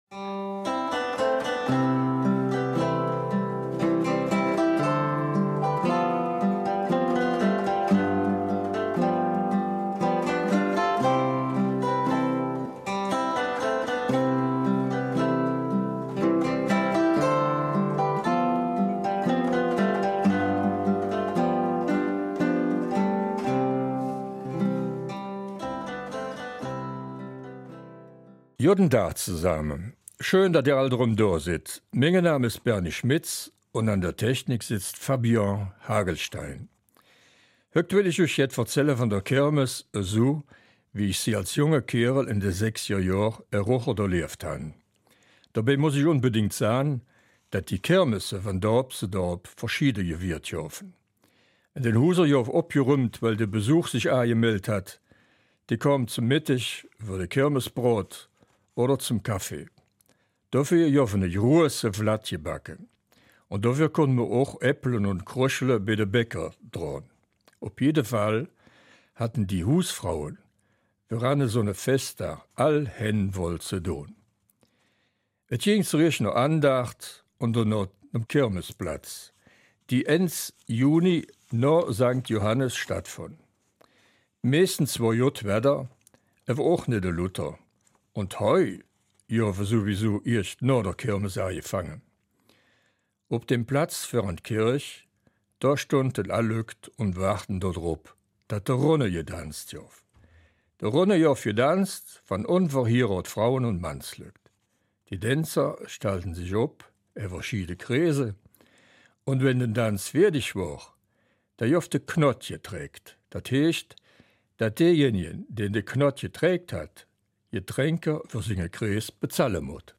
Achtung: Ohrwurm-Alarm!